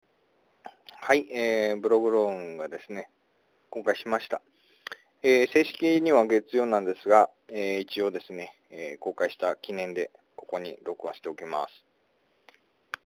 電話でブログ投稿〜BLOGROWN: